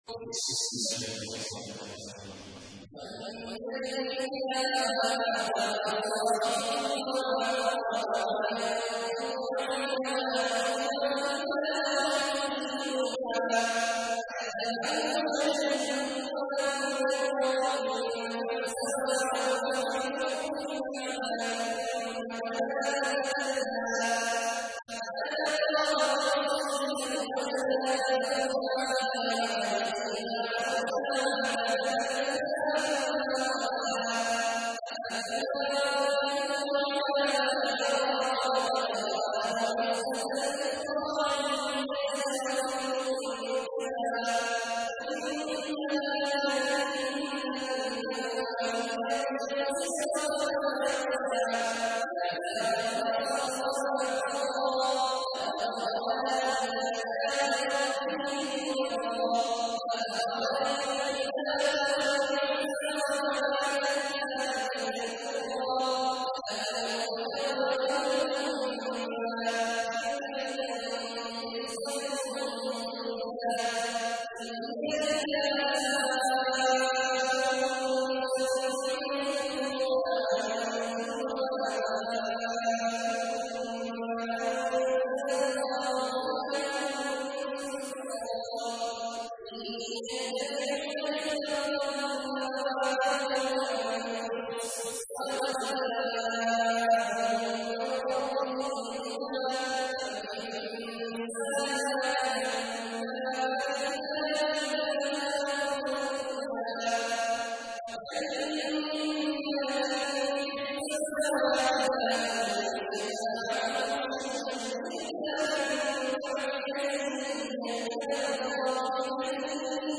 تحميل : 53. سورة النجم / القارئ عبد الله عواد الجهني / القرآن الكريم / موقع يا حسين